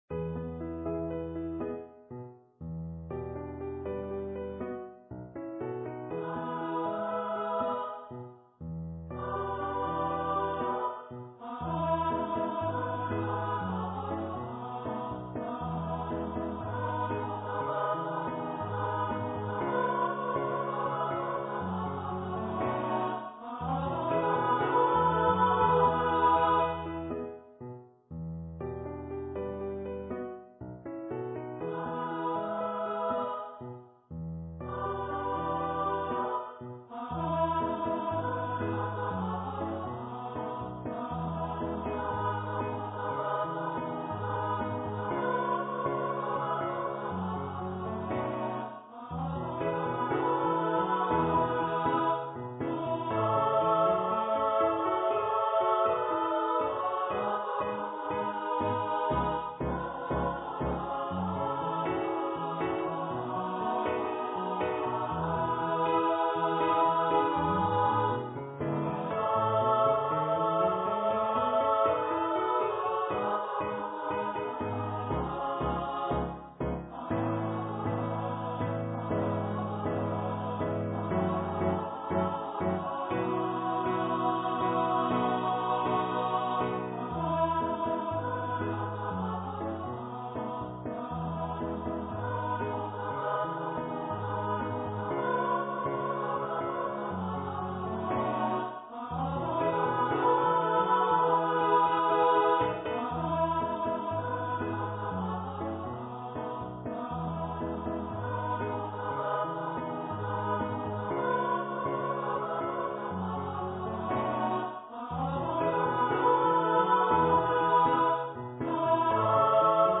for SSA choir
for choir and orchestra or piano
Choir - 3 part upper voices